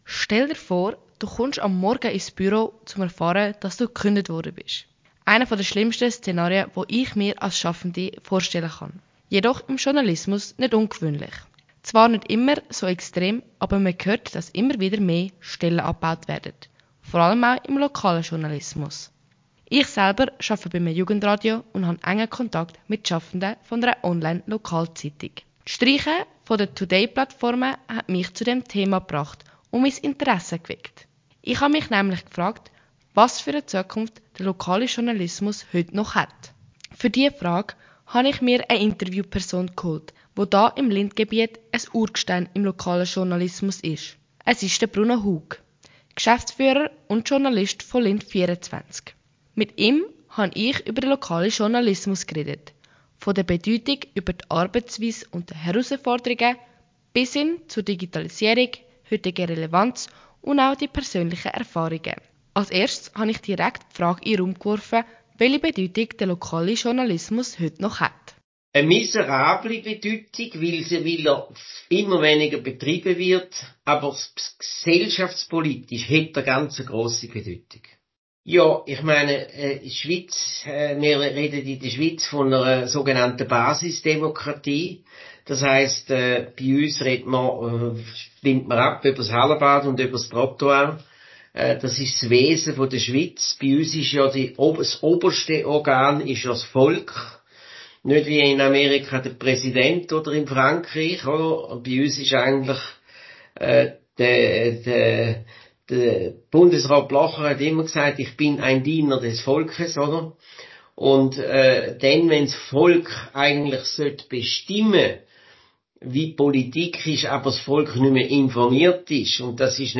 In diesem Radiobeitrag widme ich mich dem Lokaljournalismus in der Schweiz – einem Bereich, der aktuell unter Druck steht.